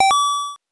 coin_2.wav